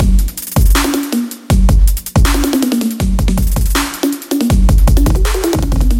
描述：强劲的踢鼓和小鼓
Tag: 170 bpm Drum And Bass Loops Drum Loops 983.61 KB wav Key : Unknown